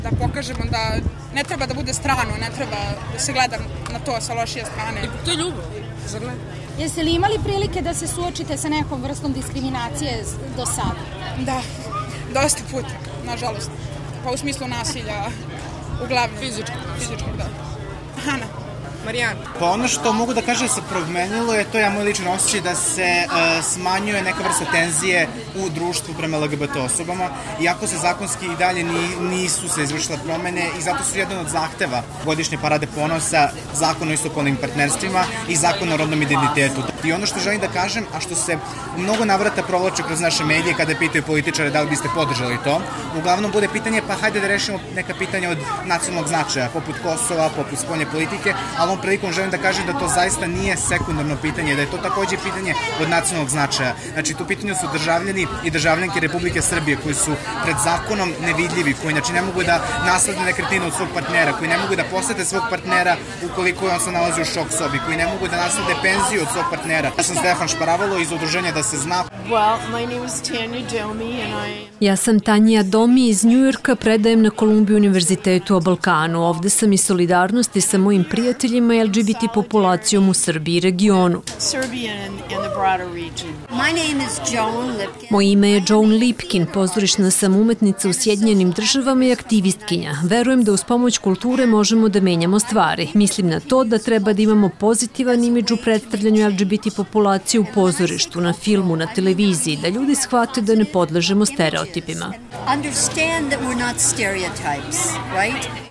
Anketa sa učesnicima beogradskog Prajda